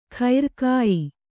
♪ khair kāyi